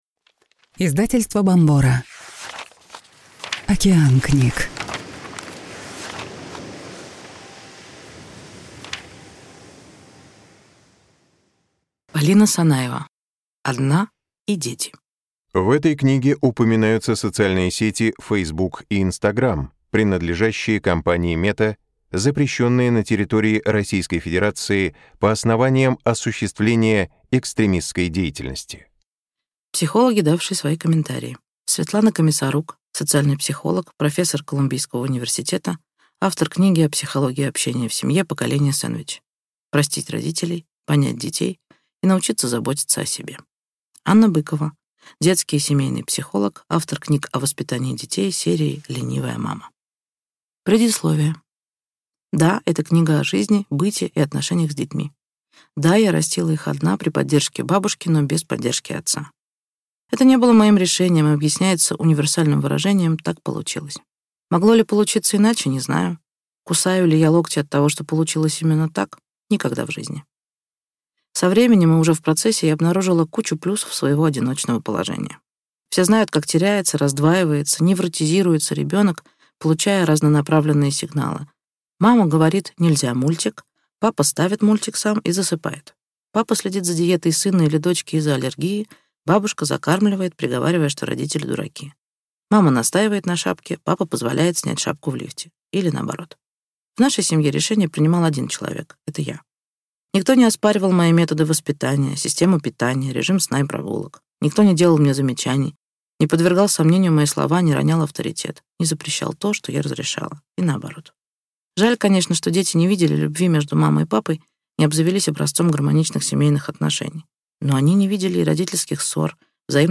Аудиокнига Одна и дети. Как вырастить детей и воспитать себя | Библиотека аудиокниг